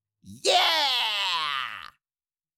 Cartoon Little Monster, Voice, Yeah 4 Sound Effect Download | Gfx Sounds
Cartoon-little-monster-voice-yeah-4.mp3